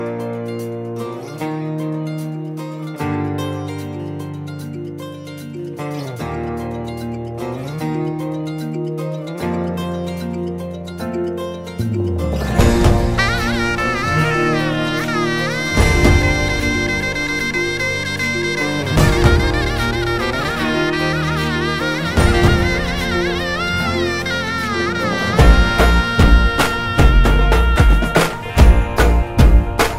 Garba